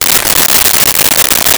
Wrapper Open 03
Wrapper Open 03.wav